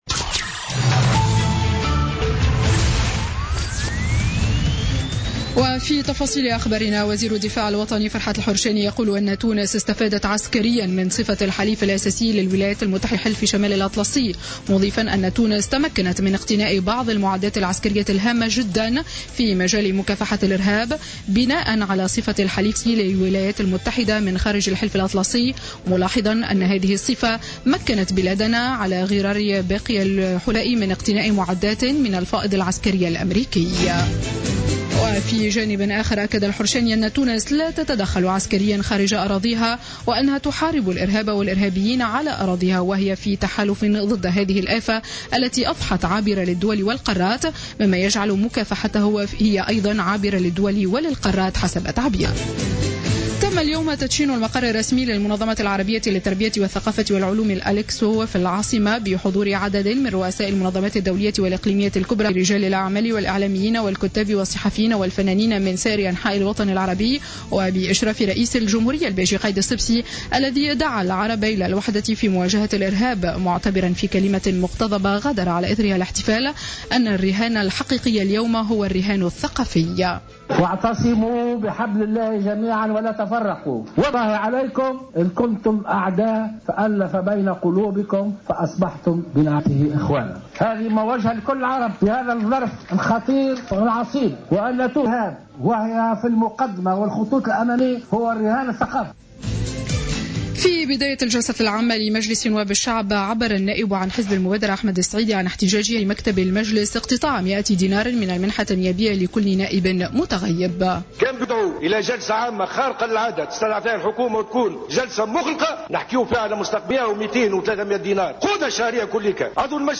نشرة أخبار منتصف النهار ليوم الثلاثاء 1 مارس 2016